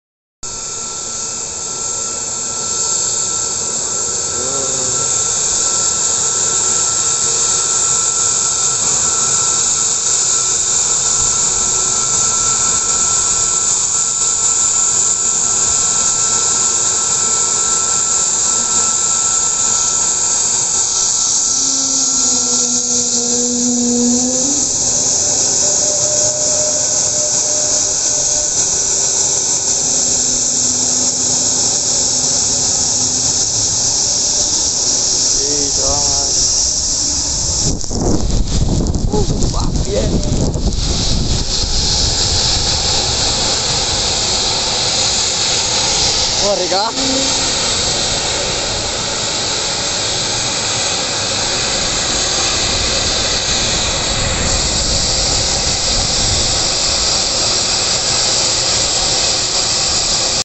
a320 neo pure sound